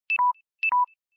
LowBattery.ogg